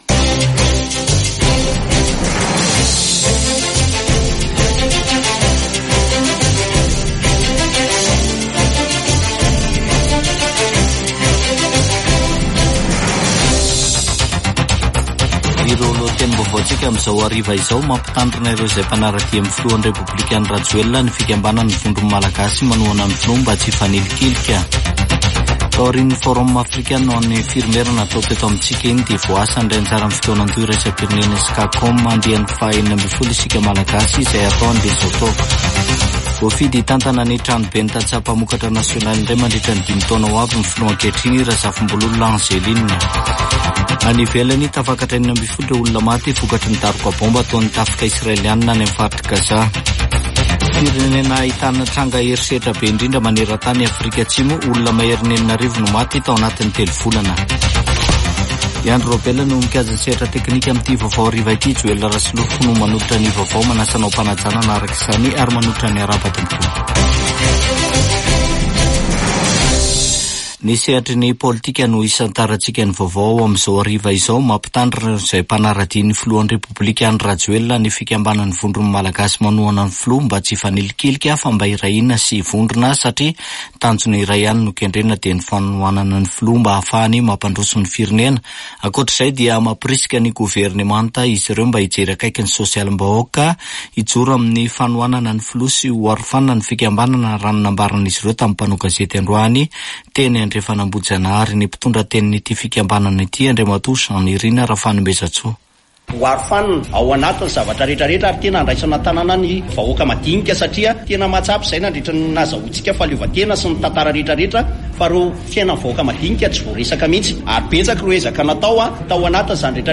[Vaovao hariva] Zoma 30 aogositra 2024